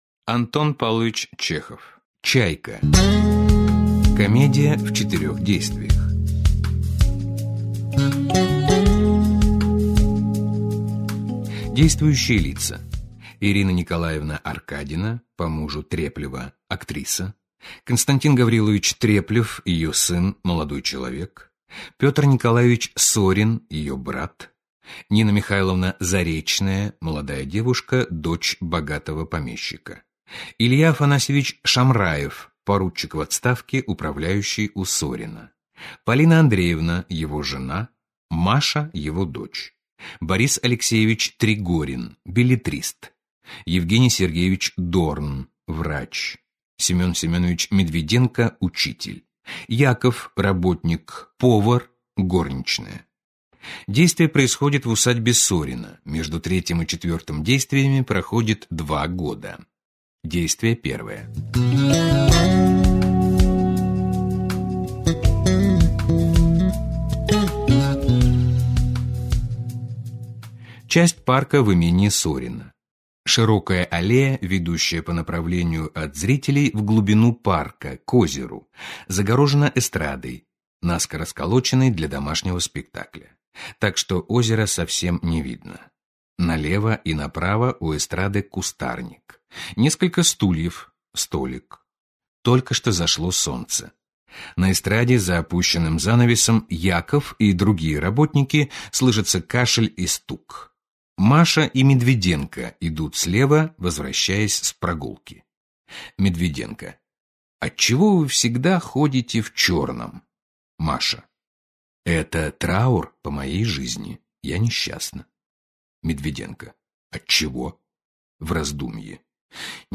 Аудиокнига Пьесы | Библиотека аудиокниг